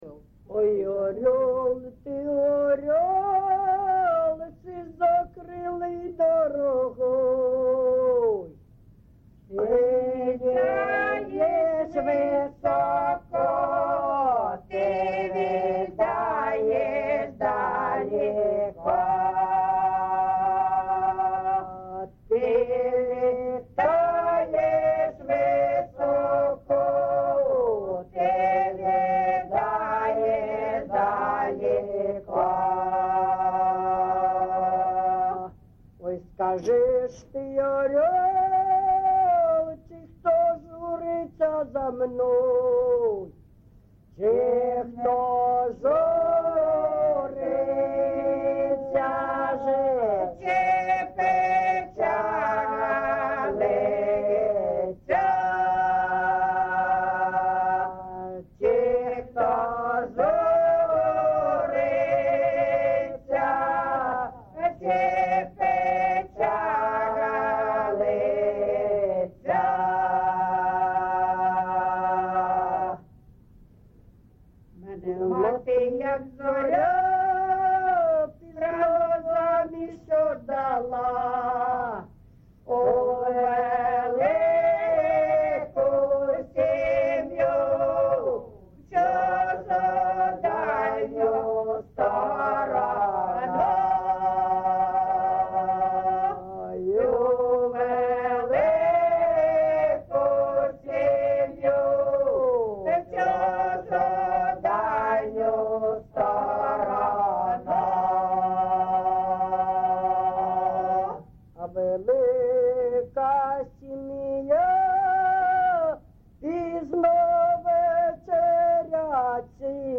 ЖанрПісні з особистого та родинного життя
Місце записус. Іскра (Андріївка-Клевцове), Великоновосілківський (Волноваський) район, Донецька обл., Україна, Слобожанщина